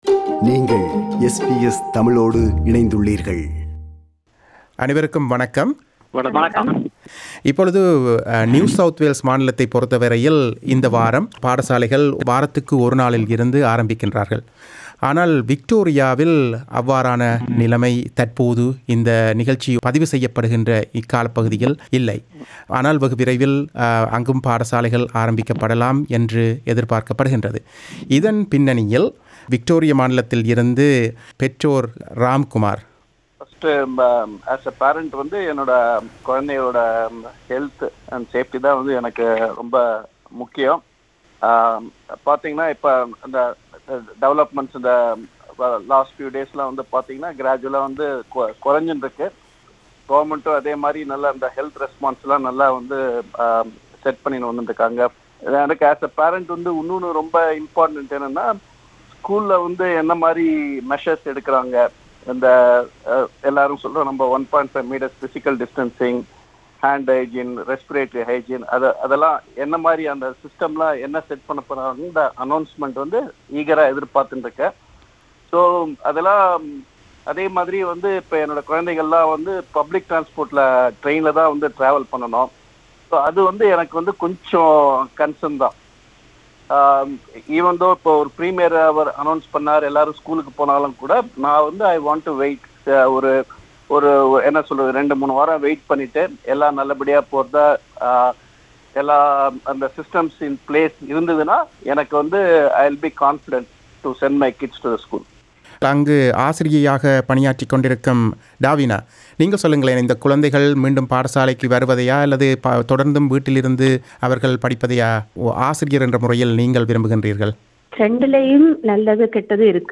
Teachers and Parents from NSW and Victoria are discussing the advantages and the concerns about sending kids back to school.